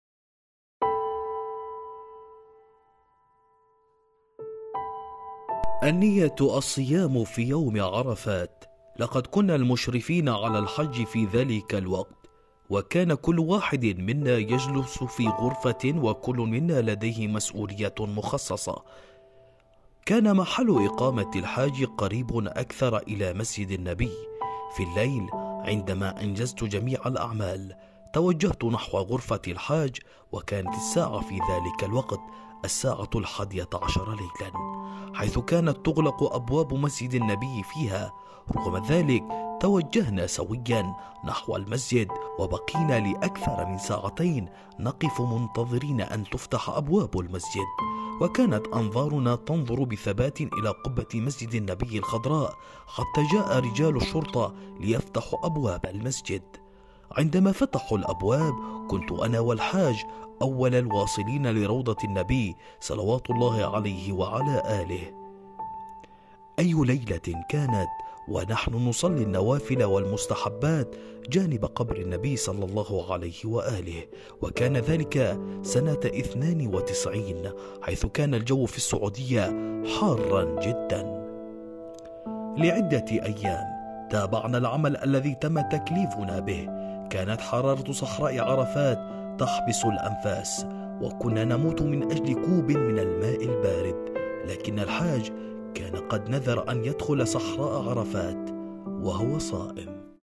المصدر: راديو المعارف _ برنامج المسافر إلى الجنة